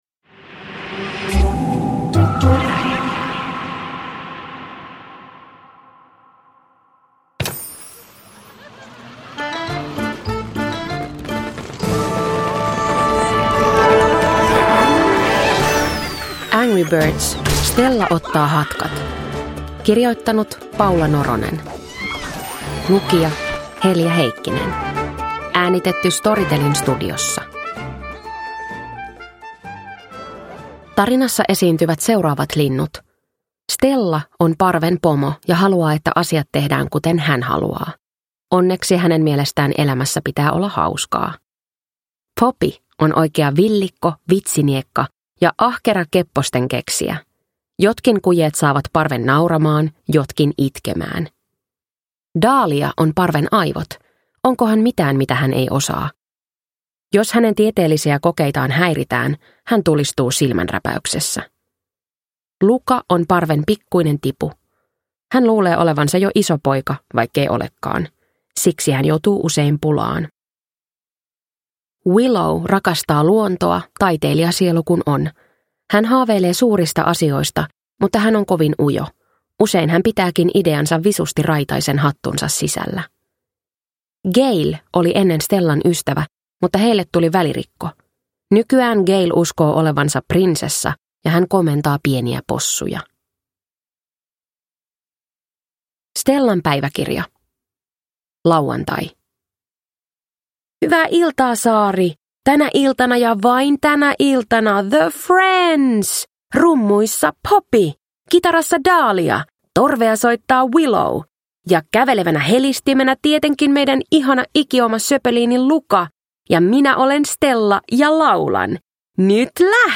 Angry Birds: Stella ottaa hatkat – Ljudbok – Laddas ner